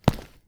concrete_step_2_-01.wav